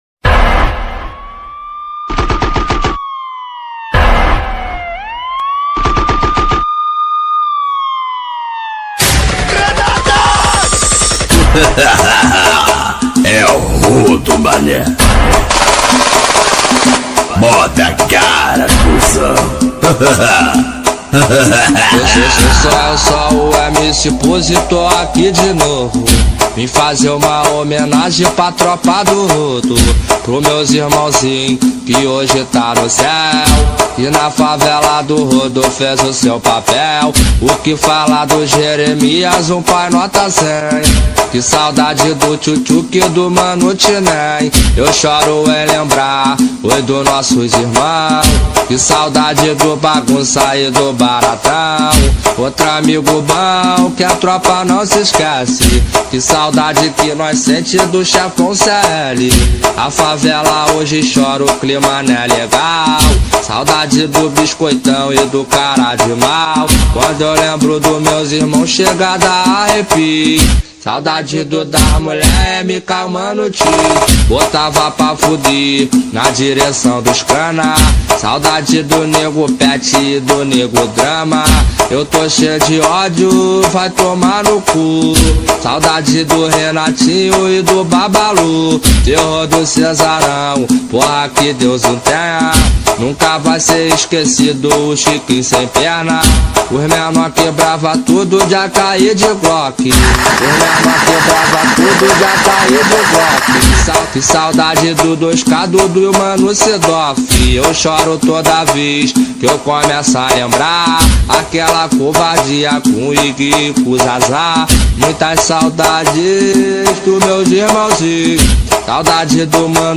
2024-02-20 03:10:12 Gênero: Funk Views